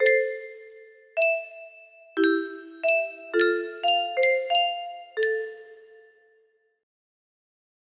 moon music box melody